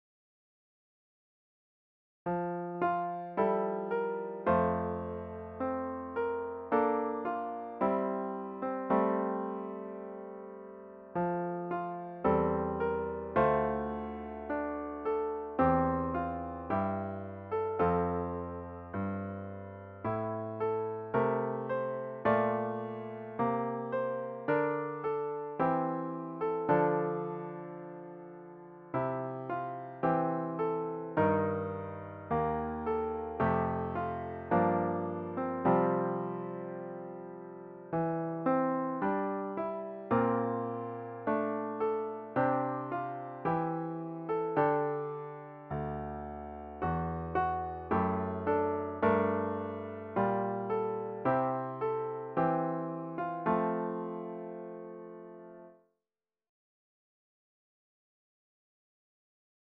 The hymn should be performed at a resolute ♪ = ca. 108.